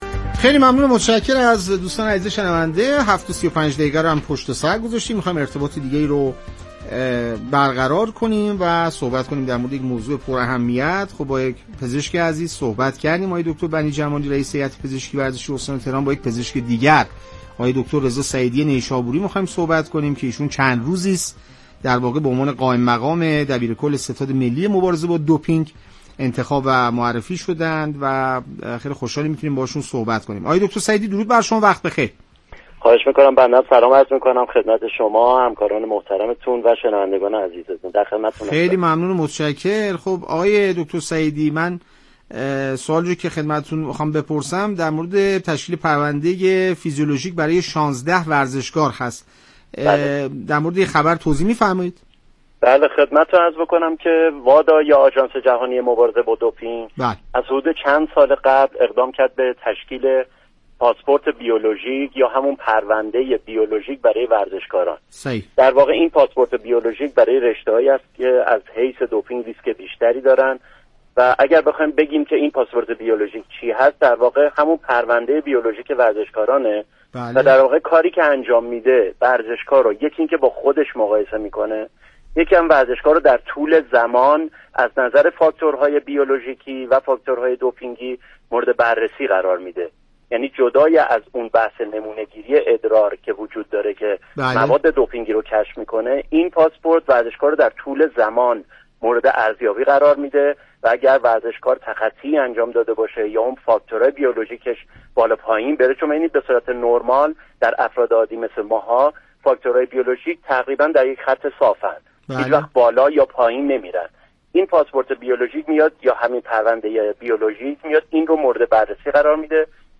در گفت وگو با بخش خبری رادیو ورزش